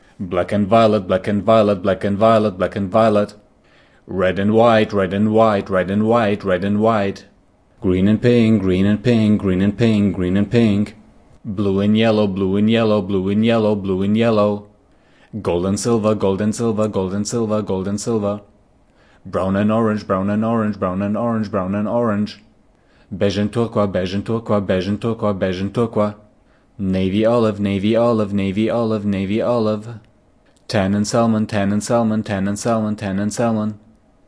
用Zoom H2录制的。
Tag: 说话 语音 文字 演讲 英语 颜色 颜色